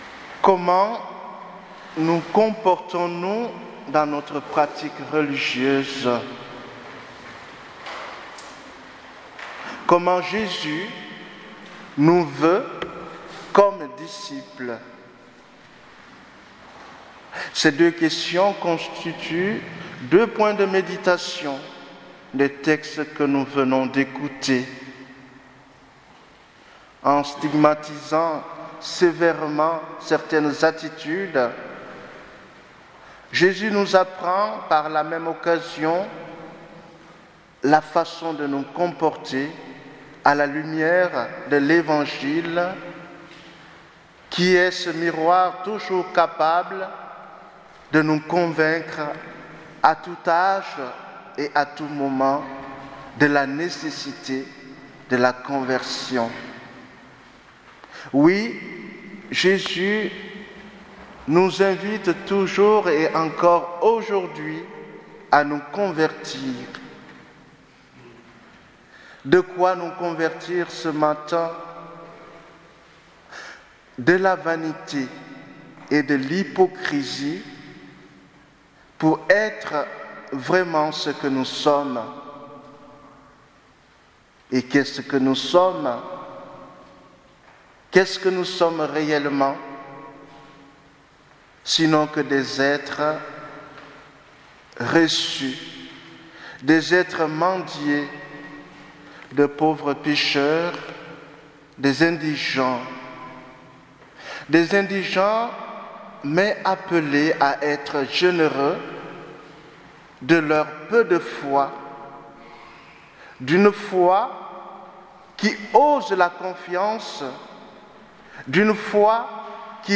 Homélie du 32ème dimanche du Temps Ordinaire